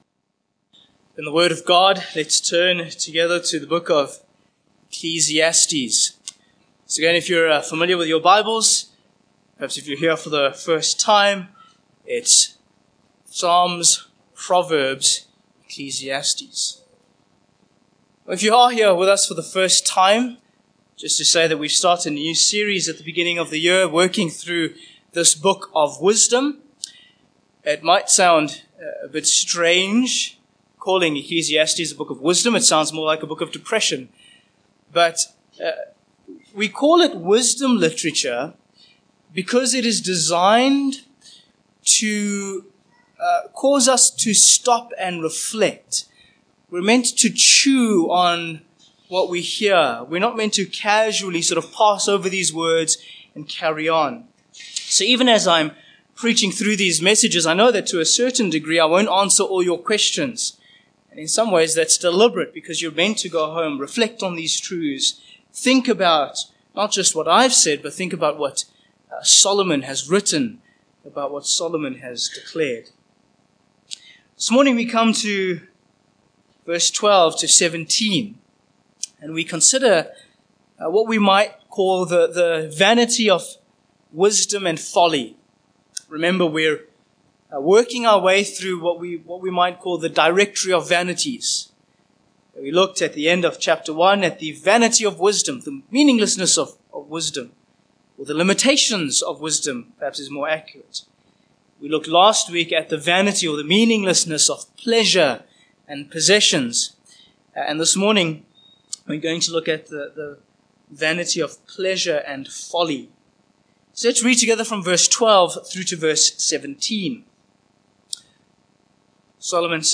Ecclesiastes 2:12-17 Service Type: Morning Passage